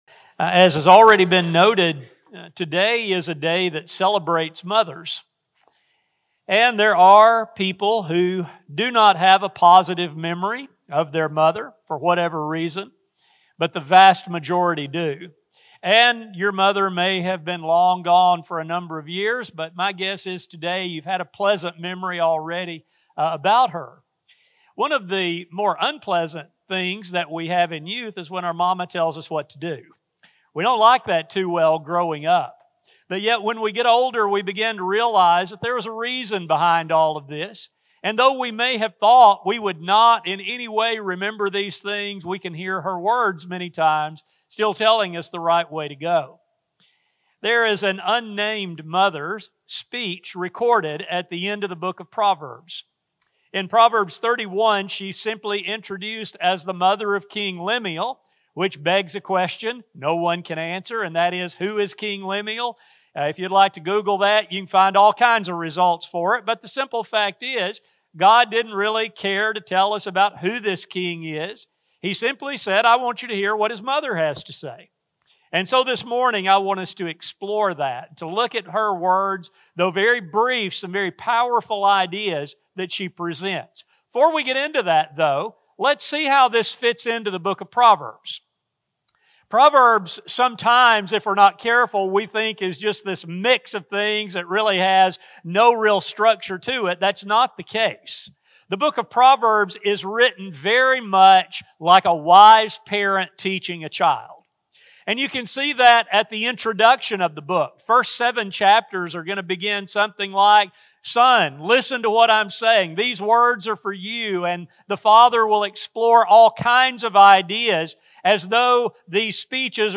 This study focuses on the three powerful ideas that she knew would make her son a success. A sermon recording